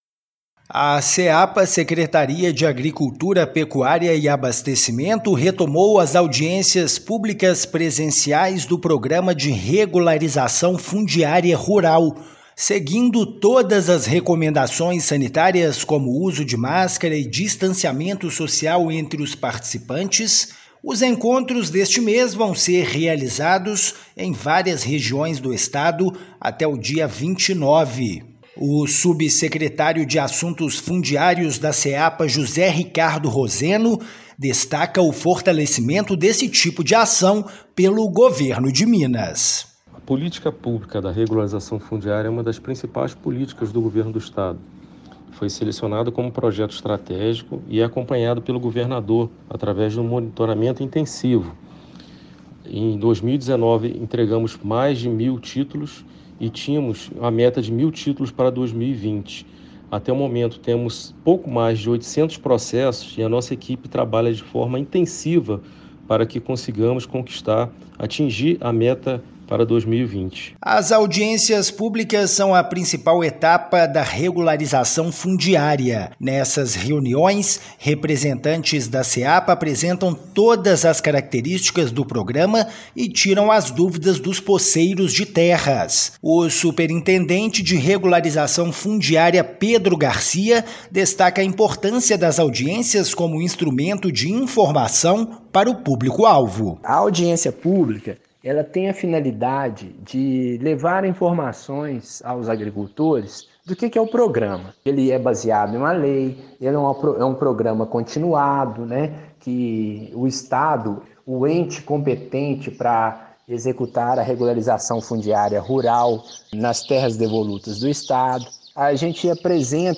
[RÁDIO] Audiências públicas do Programa de Regularização Fundiária Rural são retomadas no Estado
Governo do Estado anuncia a retomada das ações presenciais do Programa Estadual de Regularização Fundiária Rural, executado pela Secretaria de Agricultura, Pecuária e Abastecimento (Seapa), após período de suspensão em prevenção à covid-19. Até o dia 29/10, estão agendadas audiências públicas em nove municípios mineiros. Ouça a matéria de rádio.